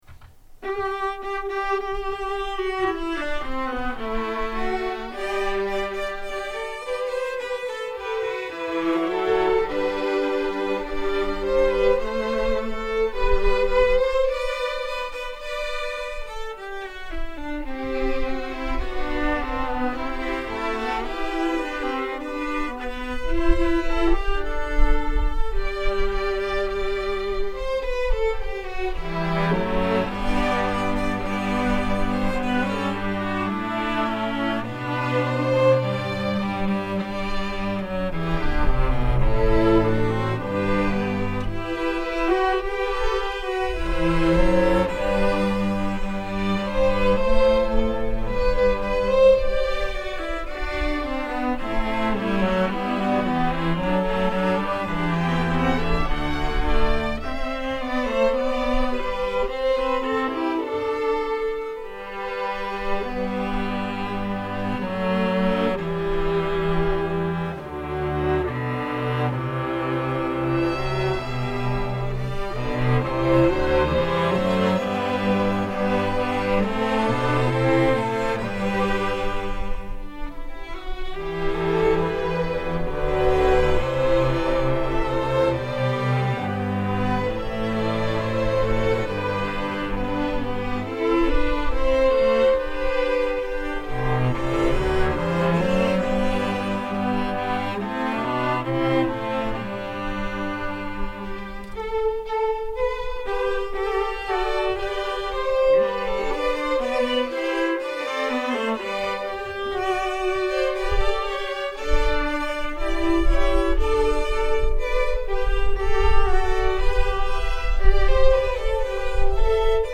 “Canzona in G” για Ορχήστρα Εγχόρδων (live